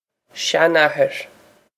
seanathair shan-a-her
Pronunciation for how to say
This is an approximate phonetic pronunciation of the phrase.